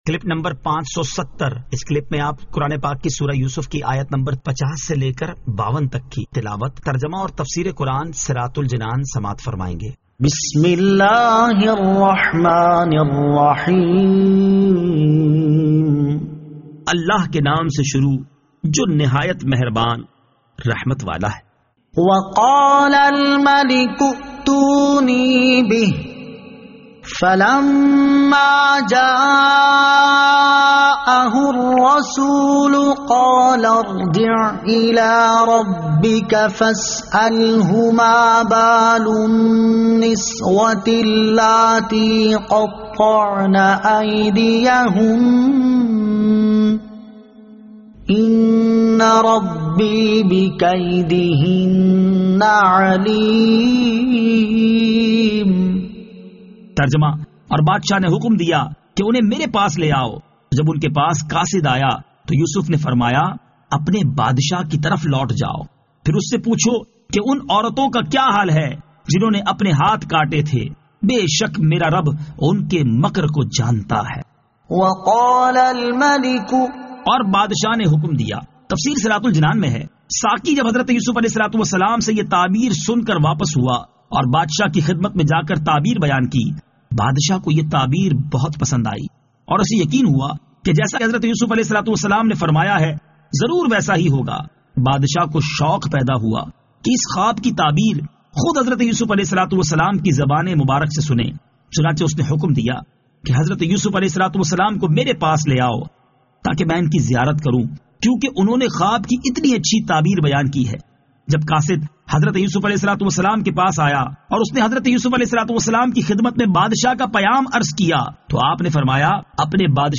Surah Yusuf Ayat 50 To 52 Tilawat , Tarjama , Tafseer